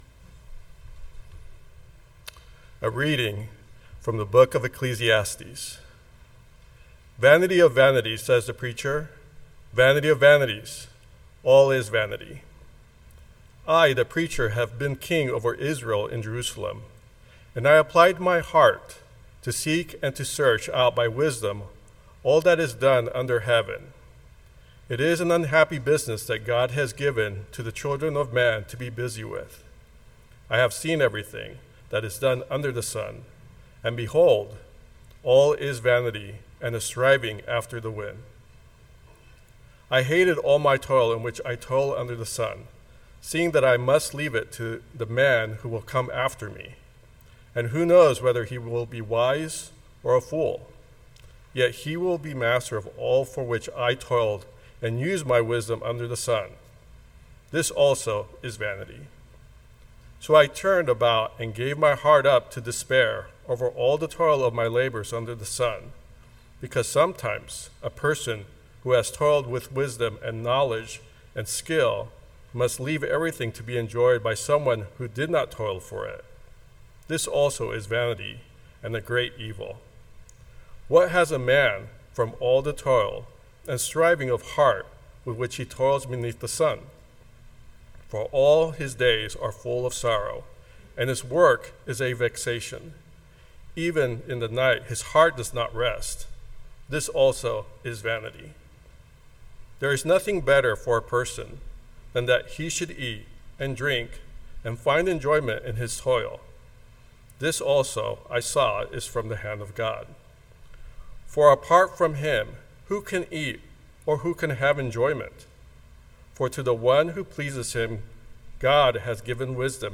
This is a stewardship sermon, but I don’t think it is the common stewardship sermon.